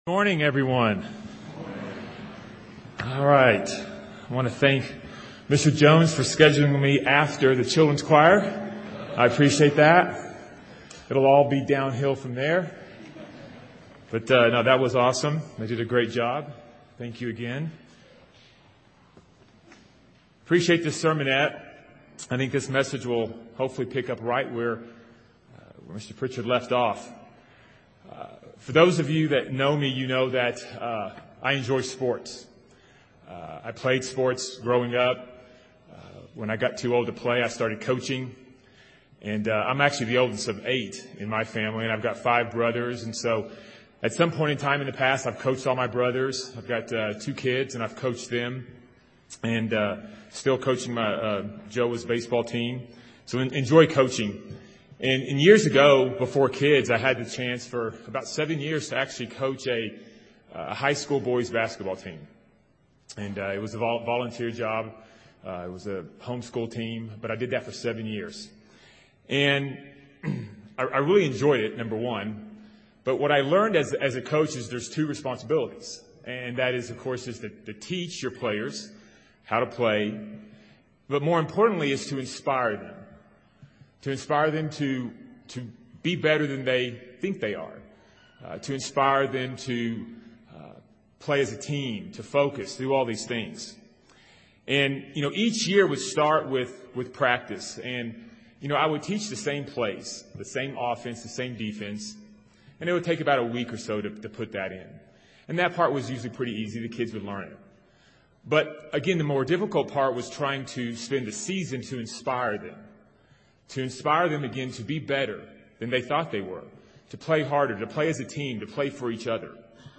This sermon was given at the Estes Park, Colorado 2022 Feast site.